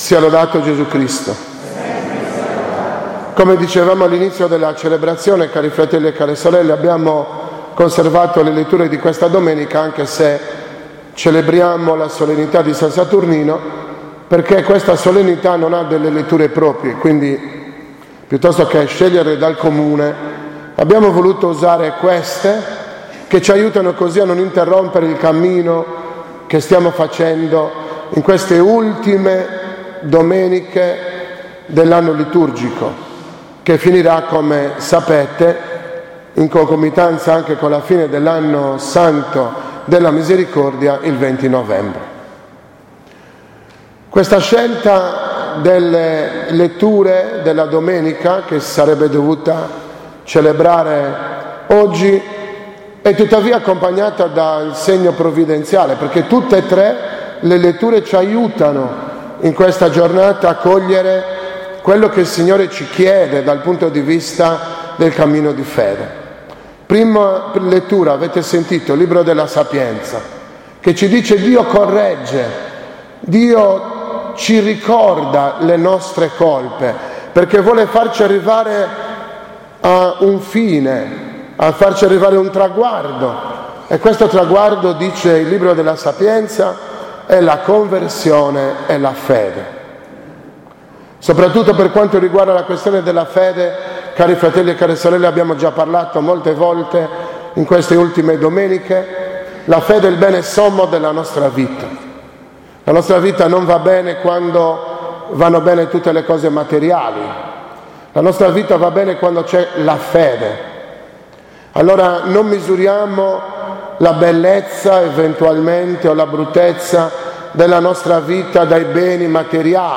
30.10.2016 – OMELIA DELLA SOLENNITÀ DI SAN SATURNINO, PATRONO DI CAGLIARI